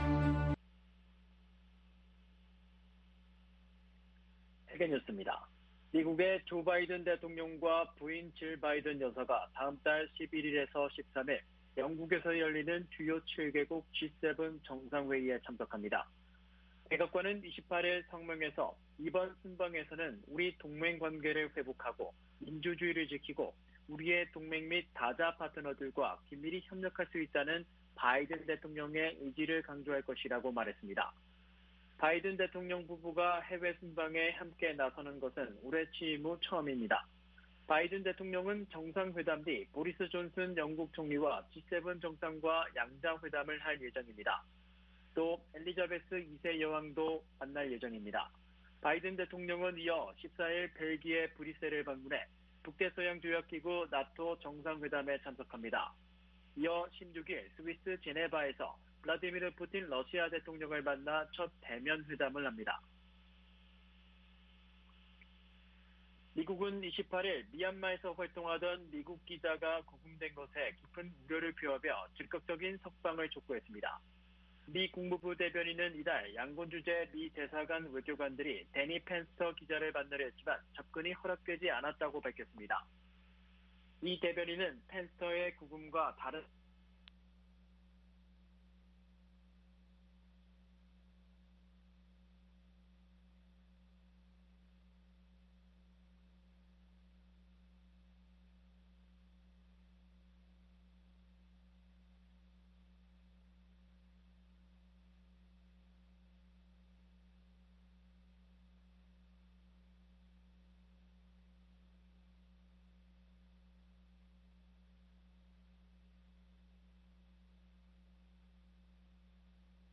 VOA 한국어 아침 뉴스 프로그램 '워싱턴 뉴스 광장' 2021년 5월 29일 방송입니다. 미국과 한국의 의원들은 미-한 정상회담 평가 화상대담에서 북한 인권문제가 부각되지 않아 실망스러웠다는 의견과 한반도 상황의 특수성을 이해해야 한다는 의견 등이 제시됐습니다. 지난주 미-한 정상회담이 민주적 가치를 강조한 것은 두 나라 관계의 깊이를 재확인시켜준 것이라고 미국의 전직 고위 관리들이 말했습니다.